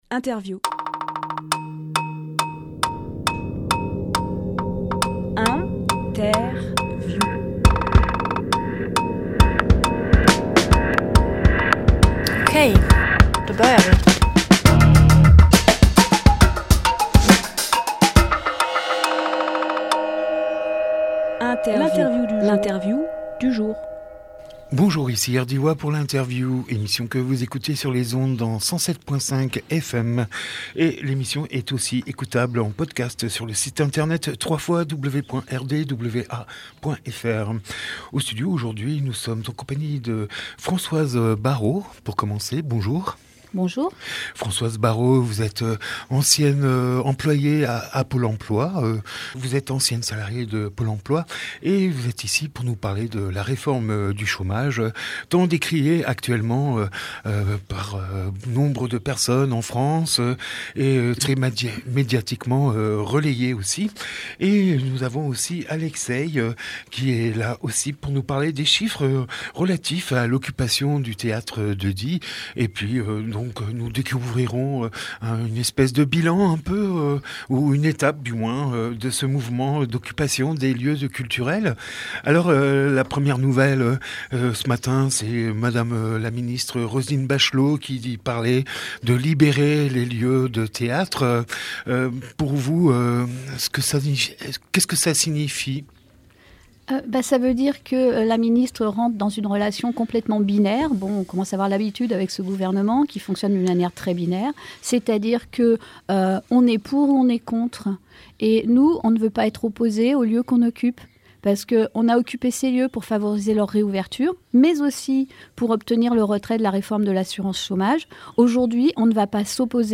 Emission - Interview 22 mai, Manifestation contre la réforme de l’assurance chômage à Die Publié le 21 mai 2021 Partager sur…
18.05.21 Lieu : Studio RDWA Durée